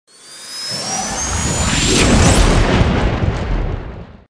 jump_in.wav